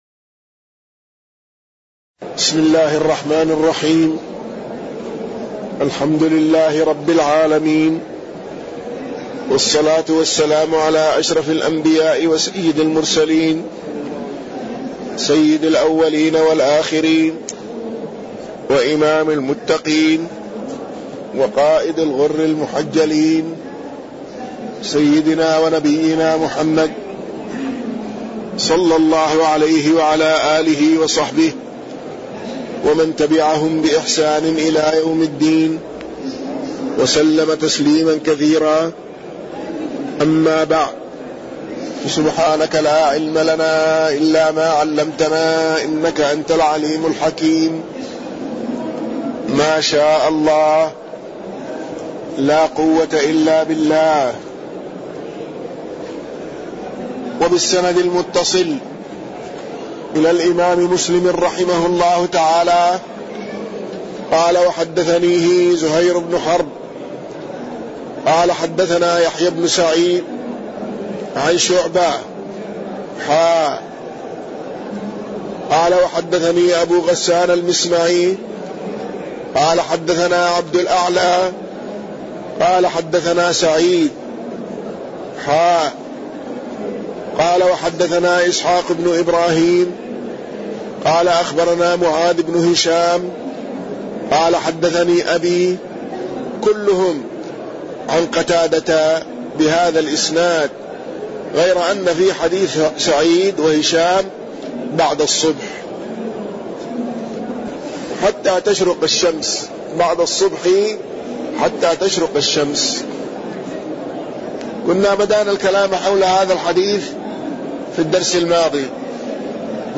تاريخ النشر ٢٨ جمادى الأولى ١٤٣١ هـ المكان: المسجد النبوي الشيخ